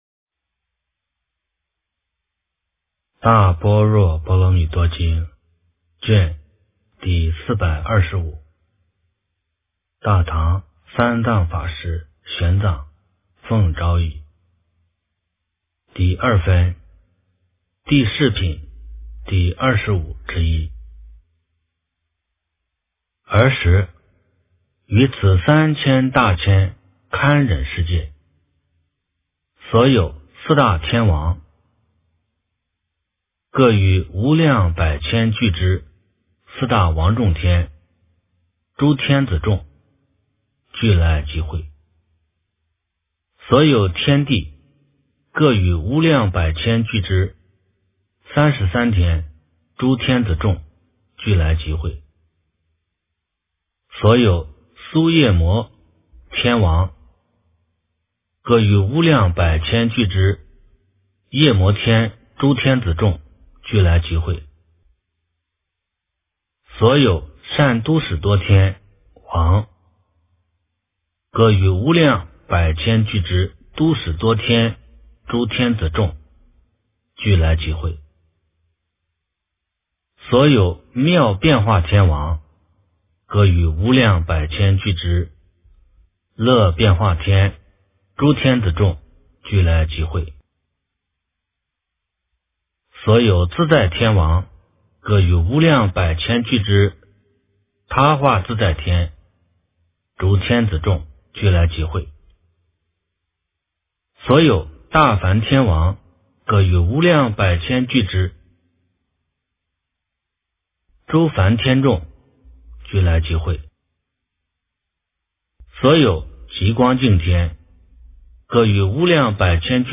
大般若波罗蜜多经第425卷 - 诵经 - 云佛论坛